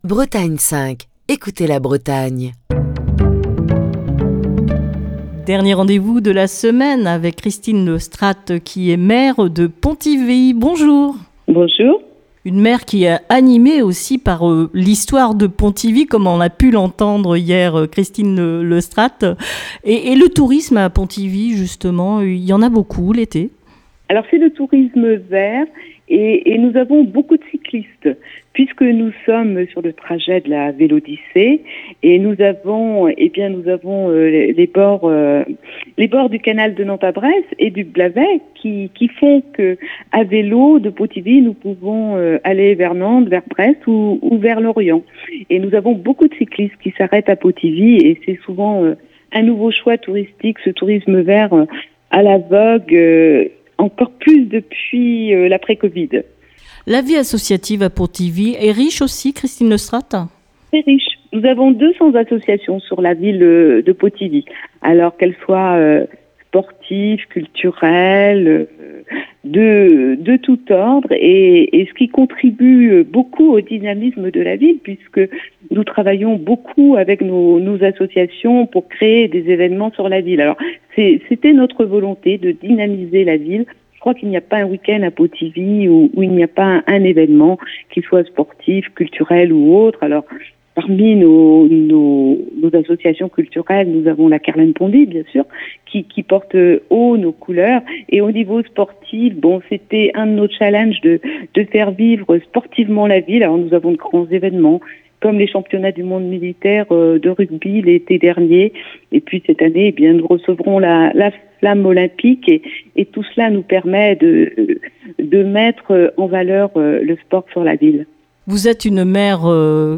au téléphone avec Christine Le Strat, la maire de Pontivy dans le Morbihan, où Destination Commune fait une dernière escale.